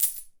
coin1.ogg